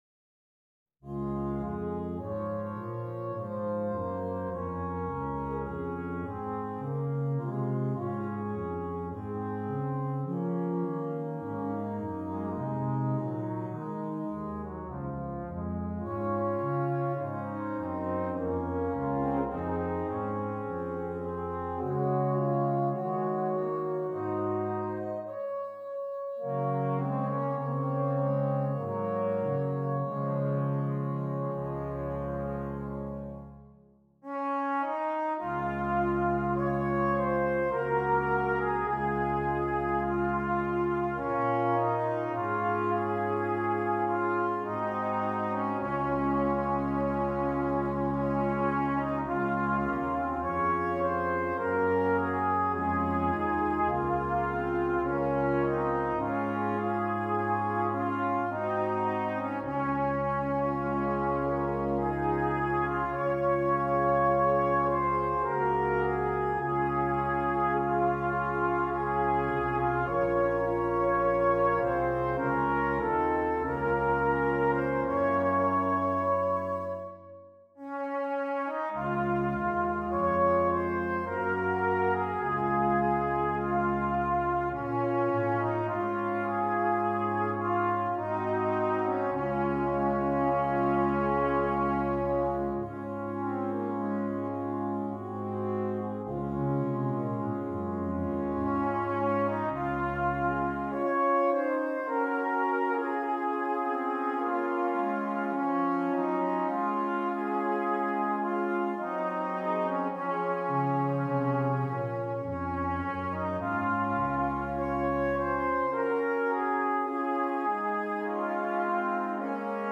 Brass Quintet
Traditional
This famous Irish folk melody